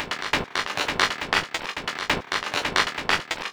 • techno synth sequence claps 136.wav
techno_synth_sequence_claps_136_9Ja.wav